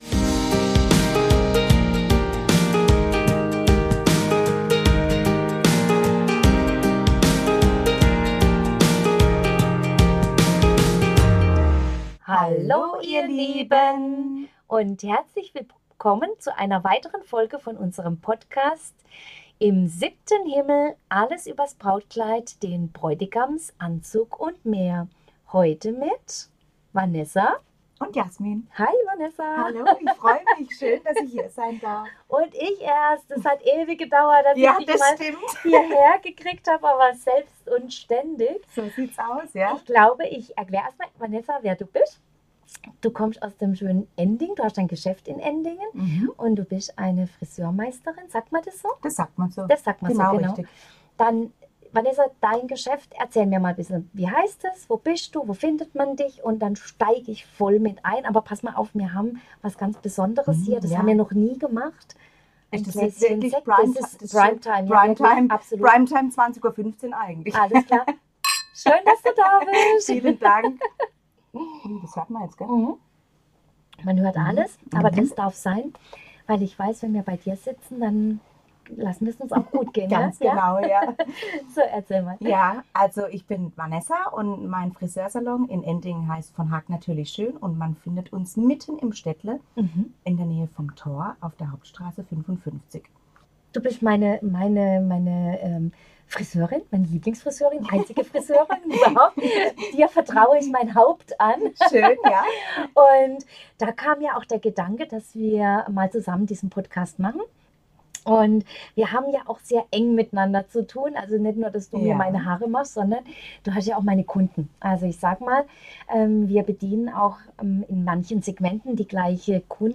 Zusammen mit einer erfahrenen Friseurmeisterin tauchen wir in die faszinierende Welt der Brautfrisuren ein. Wir sprechen über aktuelle Trends und Stilrichtungen, klären, welche Frisur wirklich zu dir passt und ob Schleier, Haarschmuck oder natürliche Eleganz die richtige Wahl ist.